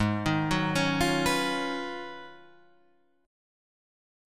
G# Minor 13th